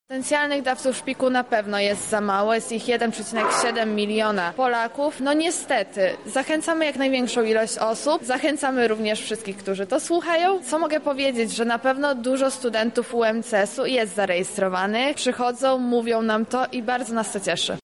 Wydarzenie odbyło się na parterze Wydziału Chemii.